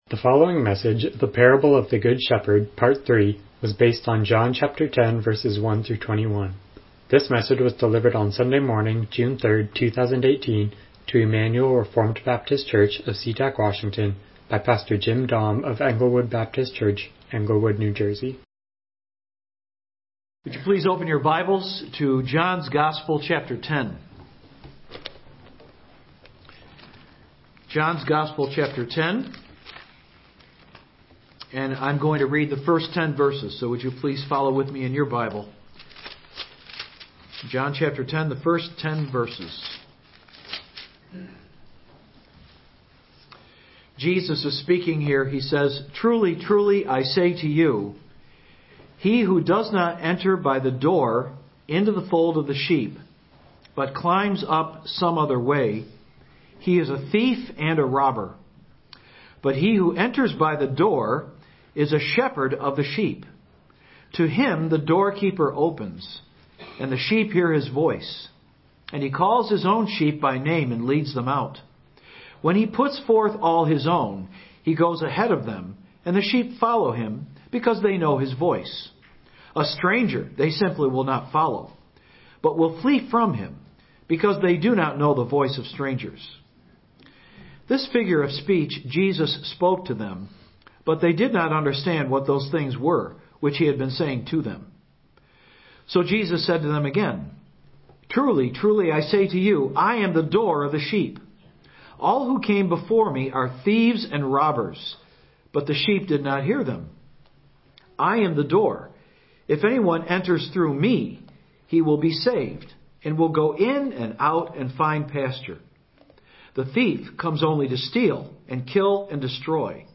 John 10:1-21 Service Type: Morning Worship « The Gift of the Holy Spirit